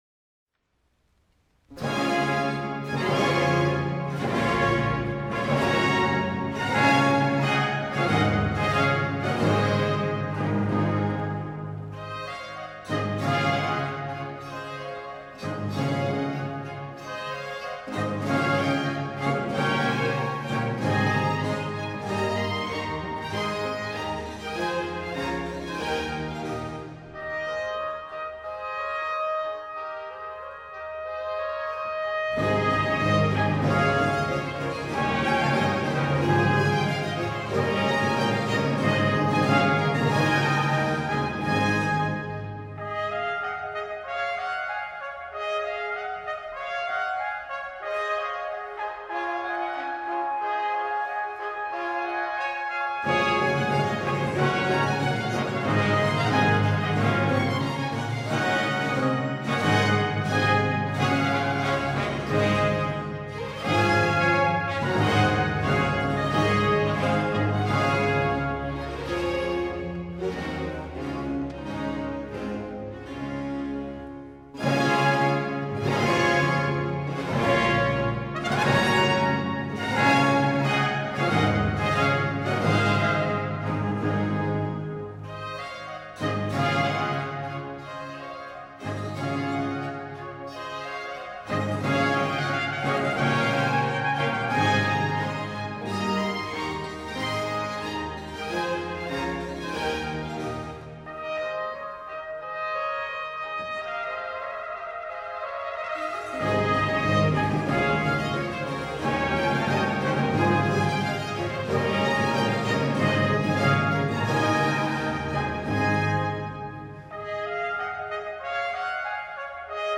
georg-friedrich-handel-deborah-hwv-51-ouverture.mp3